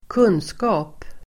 Uttal: [²k'un:ska:p]